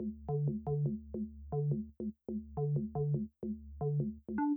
000-bass.wav